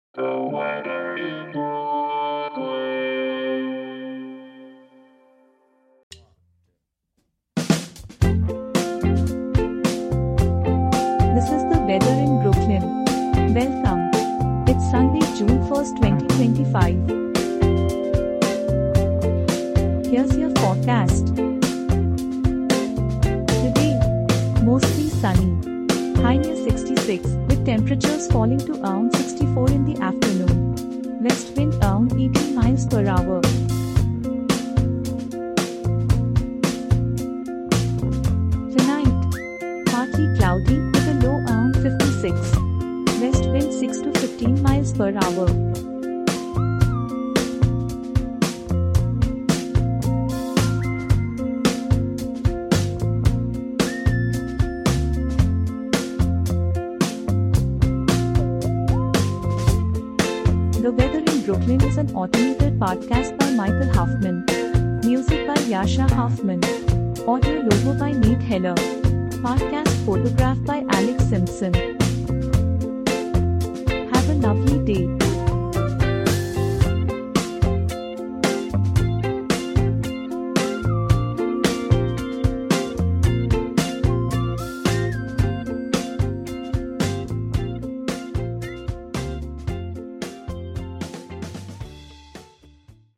generated automatically